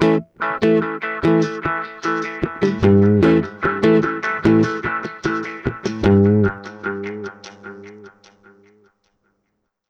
GUITARFX11-L.wav